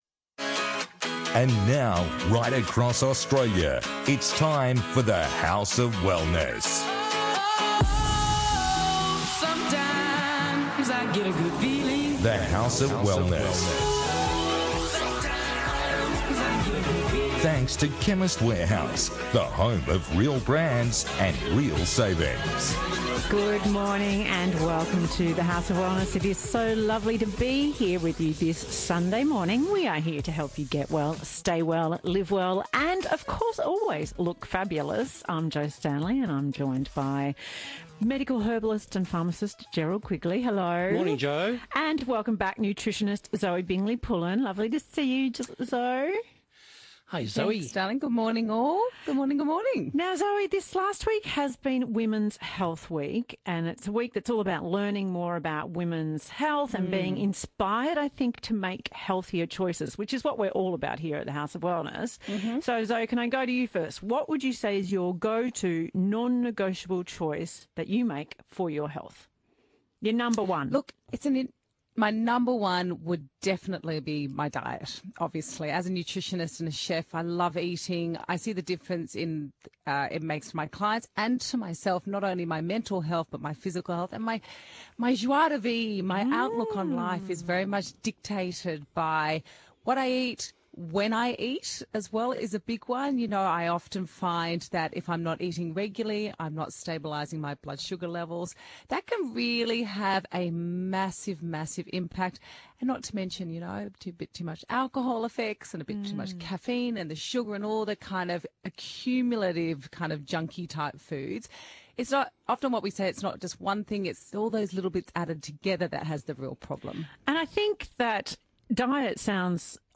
Get well, stay well, live well and look fabulous with The House of Wellness radio show.